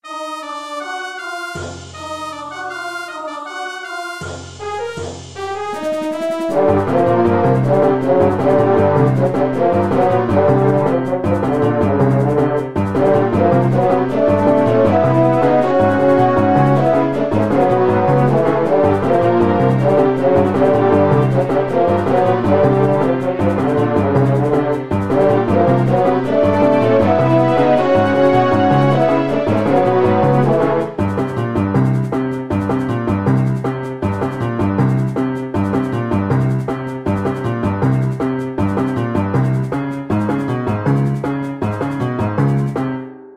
i think the piano needs to be stronger